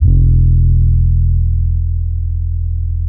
808 - LA FLAME.wav